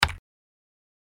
دانلود صدای کیبورد 8 از ساعد نیوز با لینک مستقیم و کیفیت بالا
جلوه های صوتی